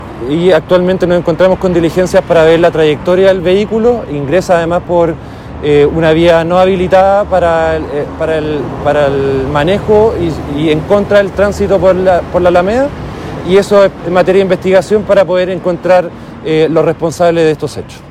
Así lo afirmó el fiscal de la Fiscalía Occidente, Jorge Lavandero.